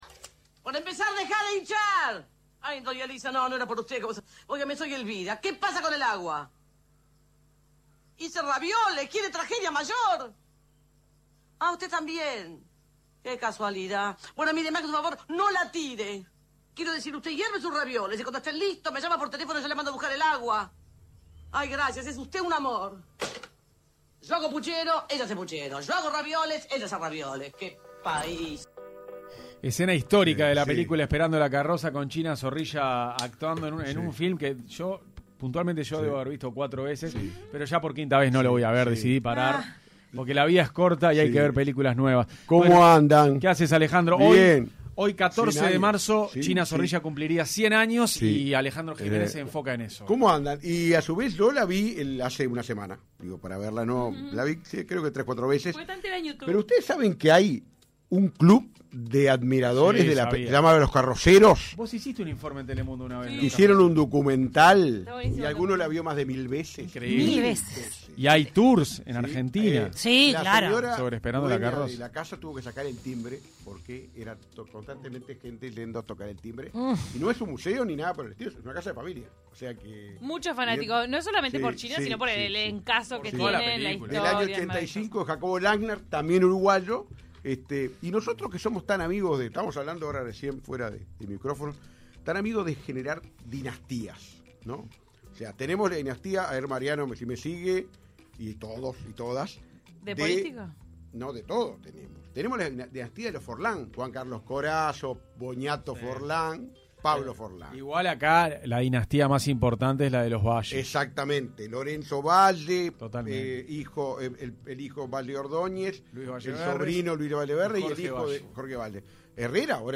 Escuchá la columna de historia completa aquí: